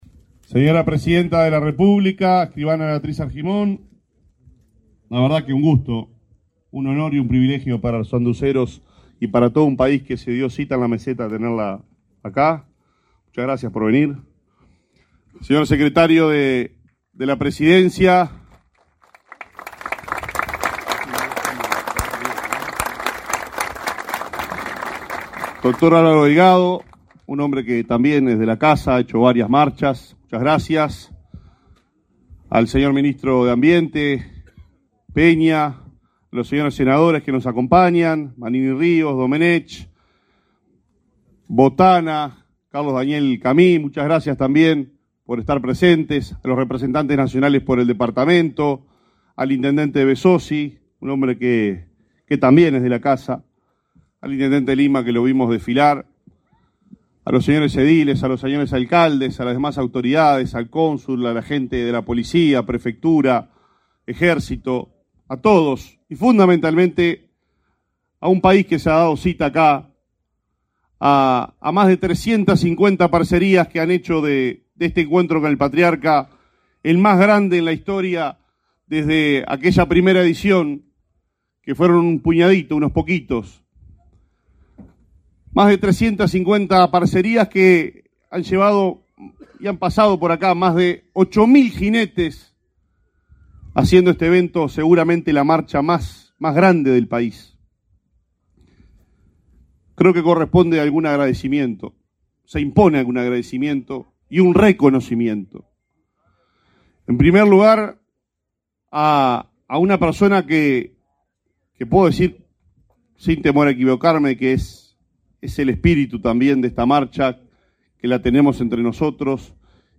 Palabras del intendente de Paysandú, Nicolás Olivera
Este 25 de setiembre se realizó el 28.° Encuentro con el Patriarca, en la Meseta de Artigas, en Paysandú.
En el evento, se expresó el intendente Nicolás Olivera.